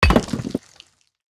axe-mining-stone-3.ogg